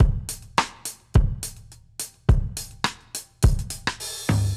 Index of /musicradar/dub-drums-samples/105bpm
Db_DrumsA_Dry_105_03.wav